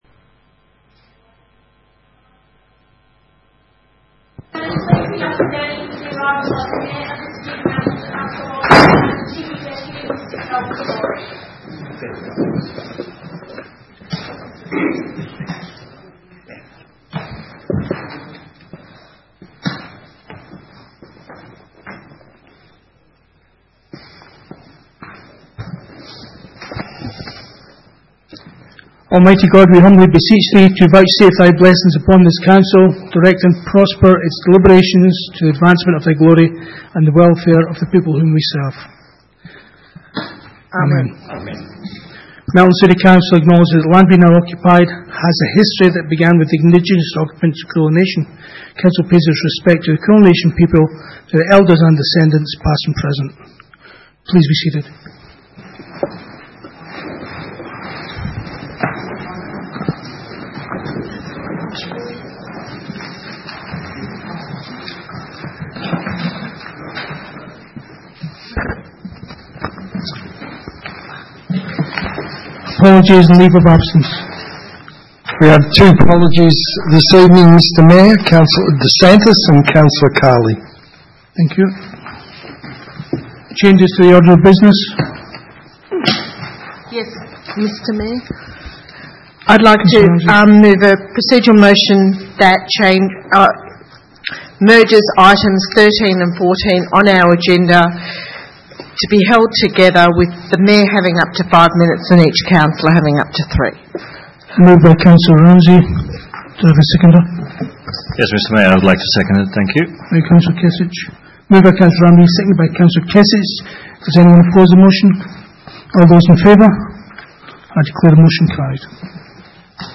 Ordinary Meeting